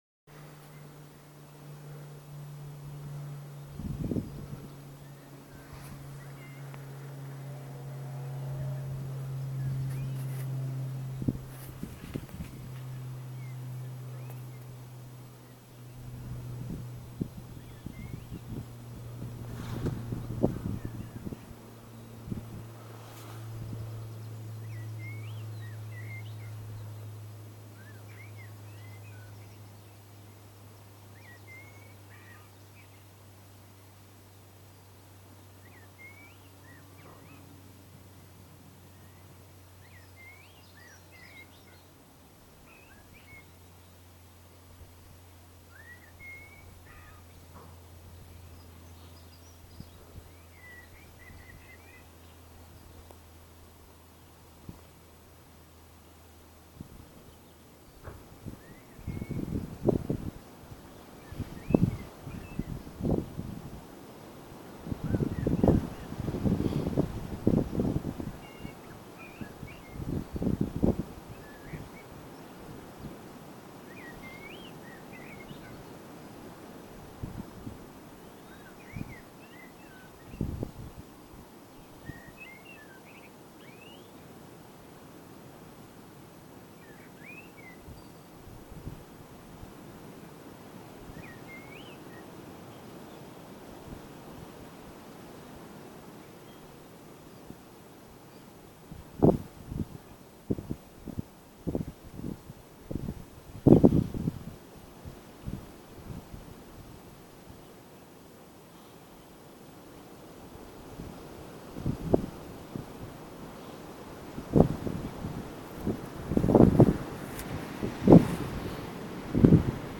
Alton, Hampshire
Field recording